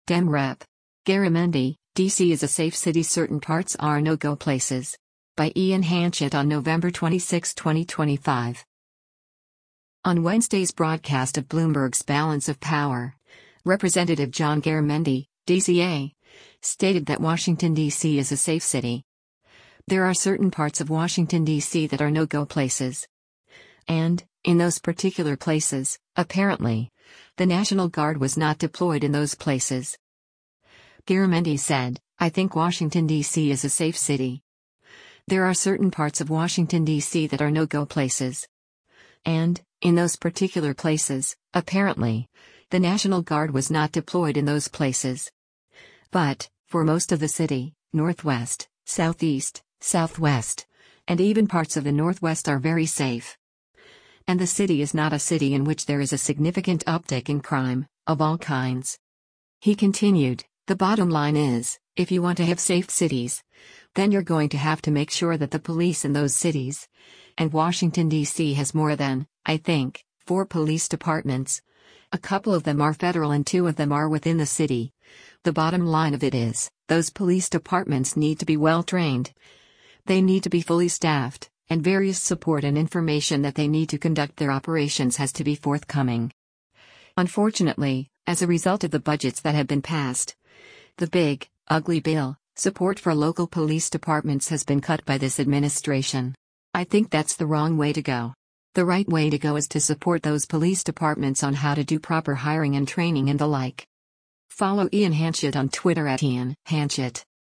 On Wednesday’s broadcast of Bloomberg’s “Balance of Power,” Rep. John Garamendi (D-CA) stated that “Washington, D.C. is a safe city. There are certain parts of Washington, D.C. that are no-go places. And, in those particular places, apparently, the National Guard was not deployed in those places.”